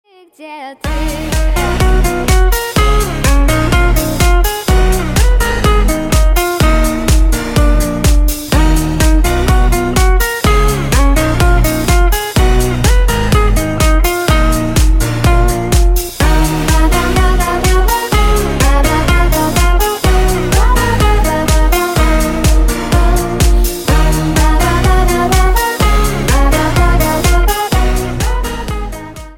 • Качество: 128, Stereo
поп
гитара
dance